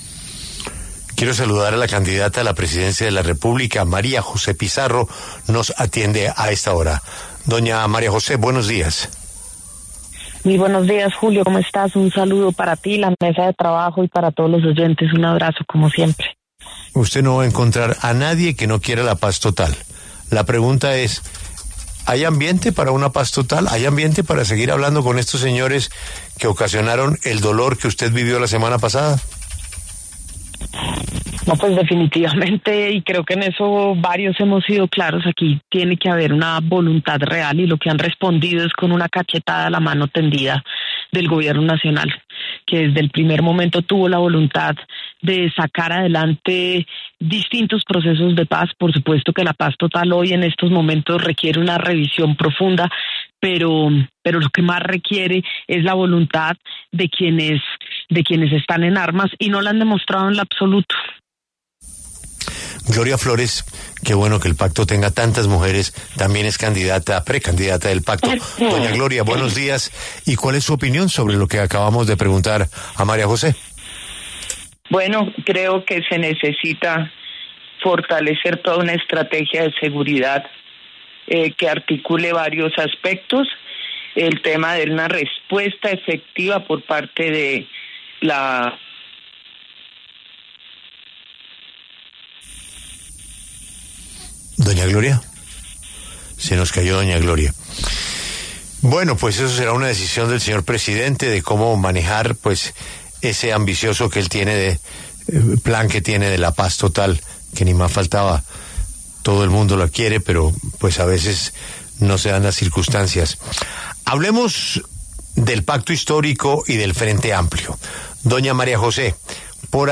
Debate: ¿Llegada de más candidatos del Pacto Histórico atomizará votos de la colectividad en 2026?